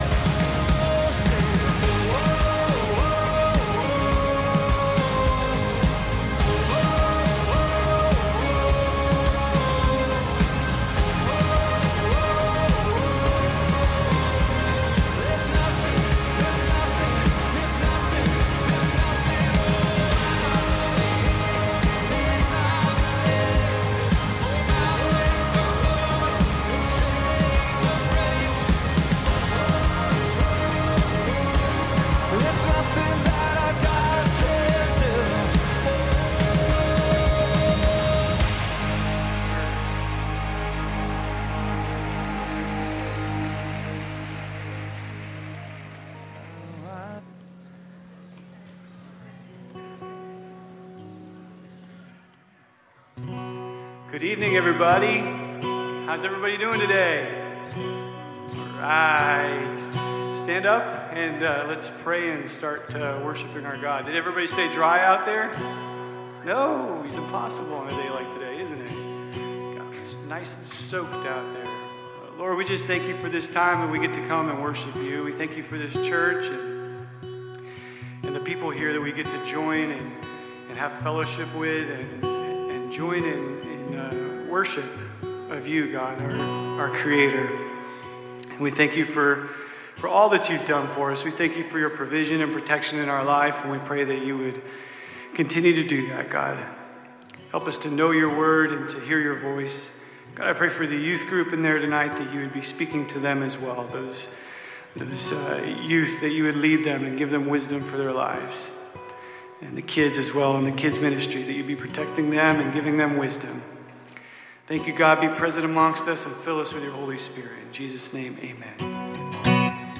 Calvary Knoxville Sunday PM Live!